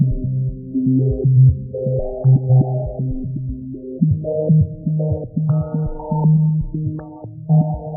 音源は基本的にハード音源のSc-8850です。